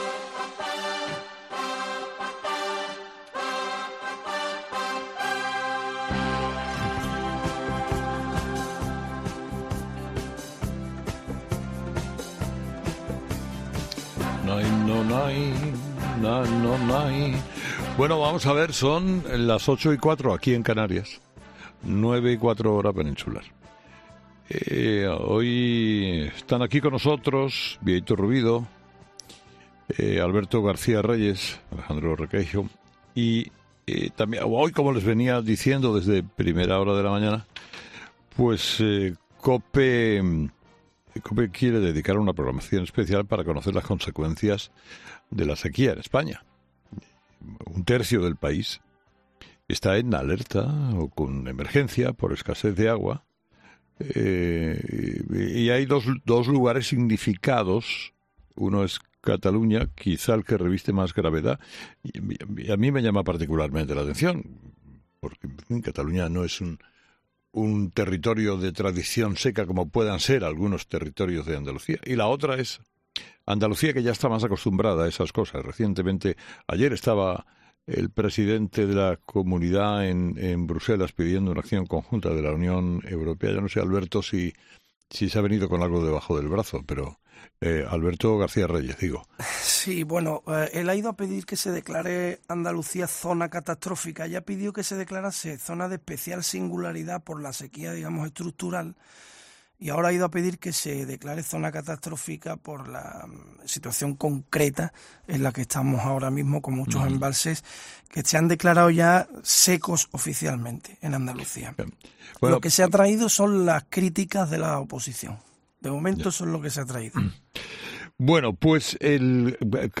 desde el embalse de Sau en Barcelona